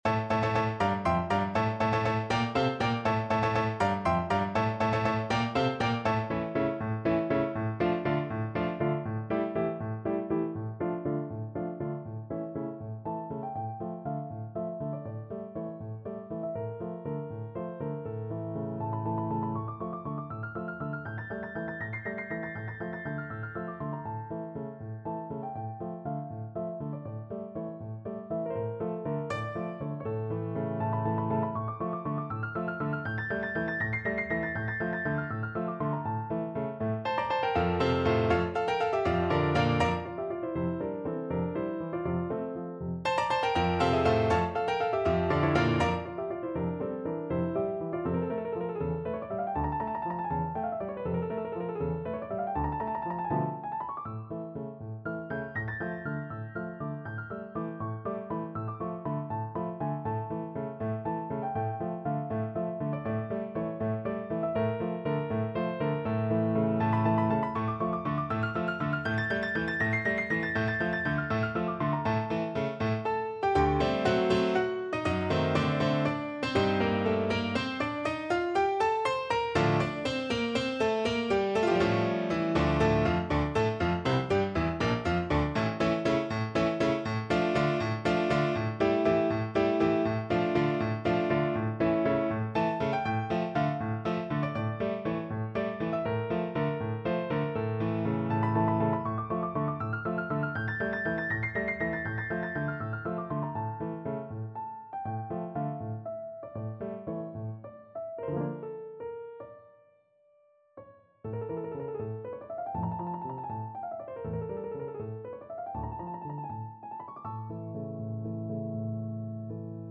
No parts available for this pieces as it is for solo piano.
D minor (Sounding Pitch) (View more D minor Music for Piano )
3/8 (View more 3/8 Music)
Allegro vivo (.=80) (View more music marked Allegro)
Piano  (View more Advanced Piano Music)
Classical (View more Classical Piano Music)
bizet_aragonaise_PNO.mp3